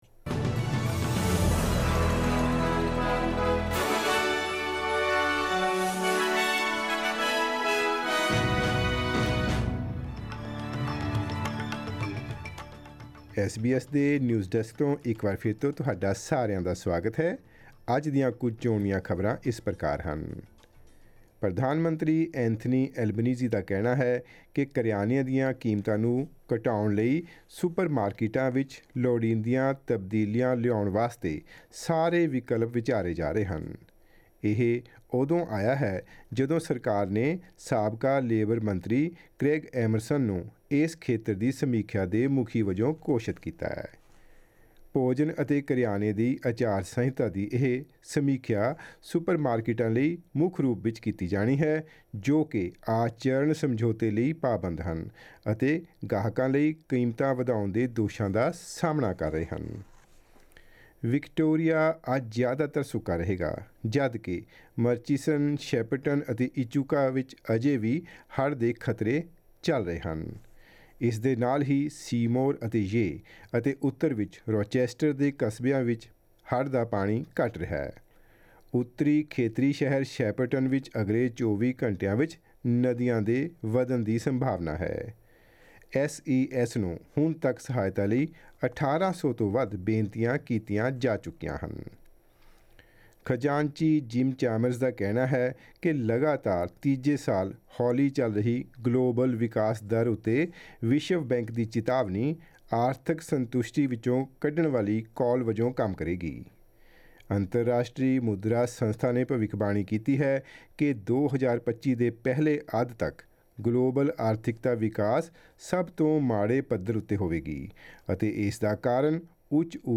SBS Punjabi Australia News: Thursday 1 June 2023